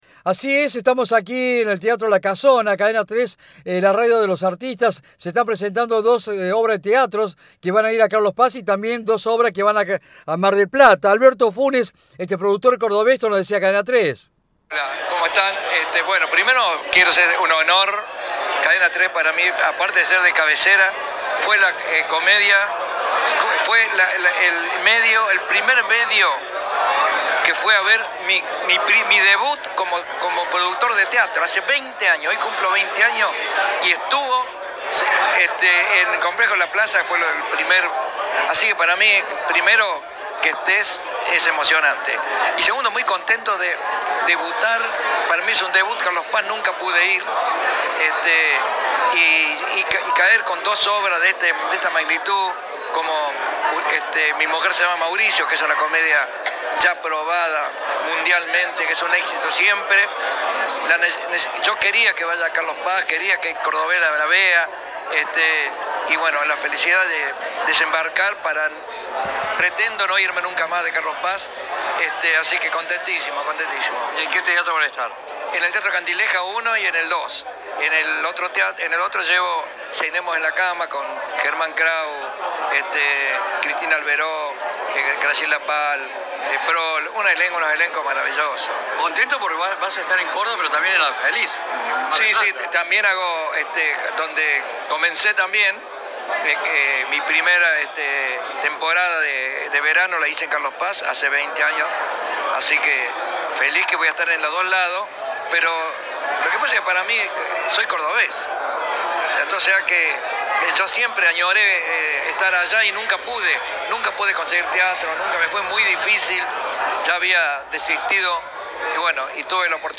En diálogo con Cadena 3, brindó más detalles al respecto.
Ahora, ya instalado en Carlos Paz para pasar el verano, visitó los estudios de la radio y brindó más detalles sobre cada una de las obras.